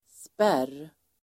Uttal: [spär:]